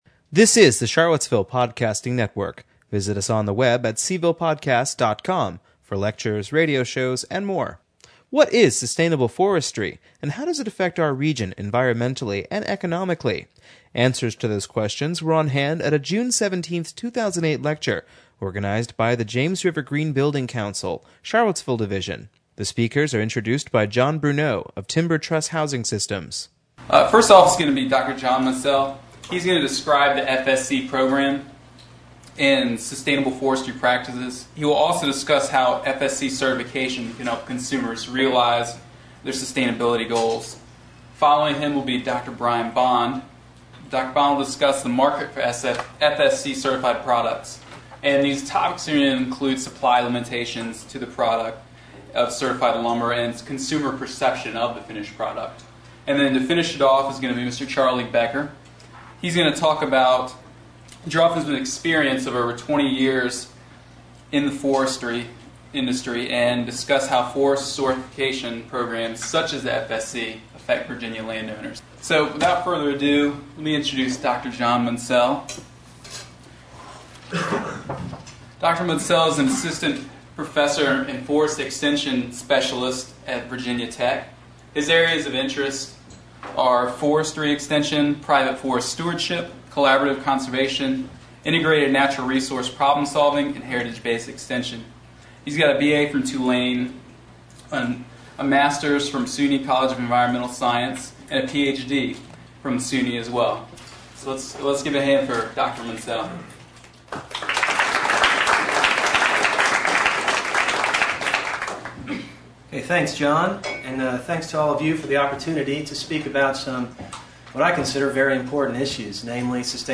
What is sustainable forestry and how does it affect our region environmentally and economically? This lecture organized by the James River Green Building Council Charlottesville Division will answer that question and many others as part of a series of lectures dealing with the LEED Green Building Rating System.
We have assembled three speakers who together give a very balanced view of the issue.